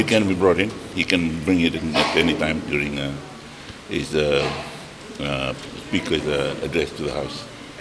Speaking to journalists outside Parliament today, Rabuka says the next meeting will be held on Wednesday or Thursday.